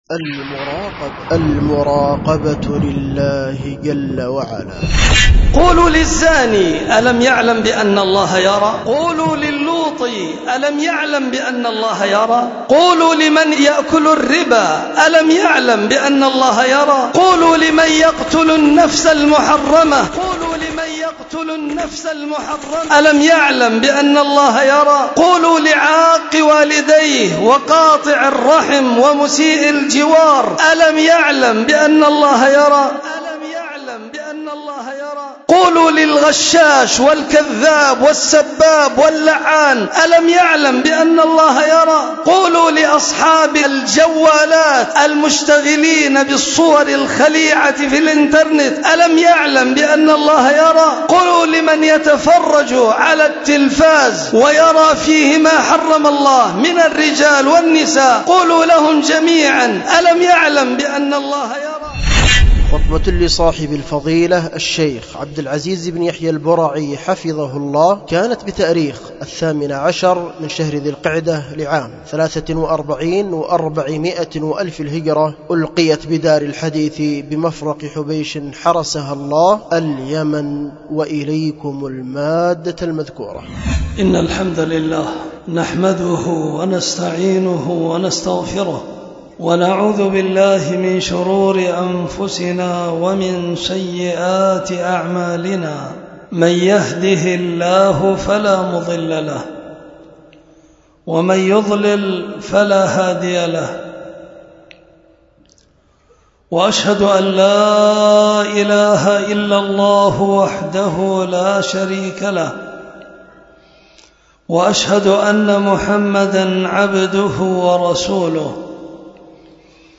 خطبة
ألقيت بدار الحديث بمفرق حبيش